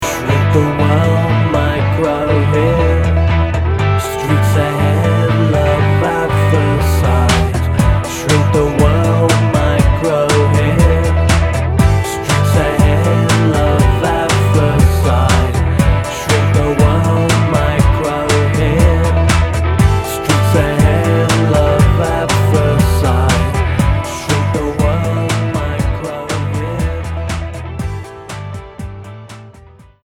The results cohere into a sonic juggernaut.